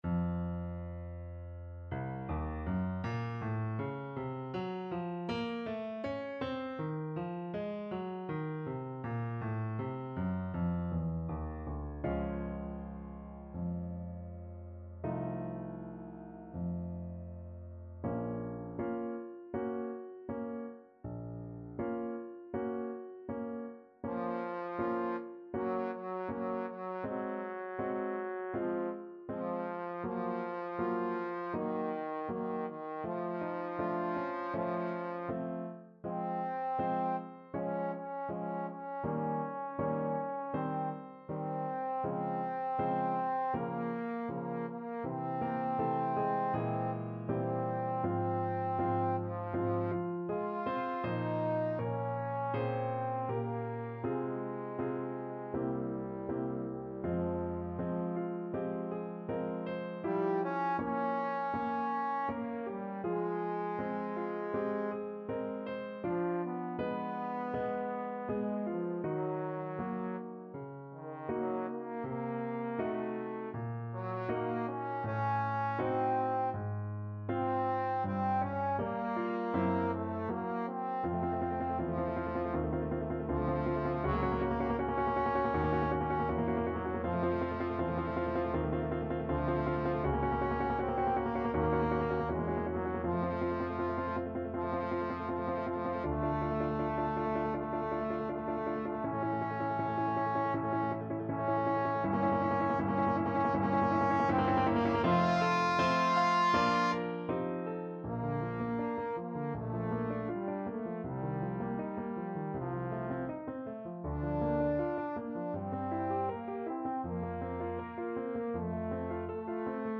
Trombone
Bb minor (Sounding Pitch) (View more Bb minor Music for Trombone )
4/4 (View more 4/4 Music)
~ = 100 Molto moderato =80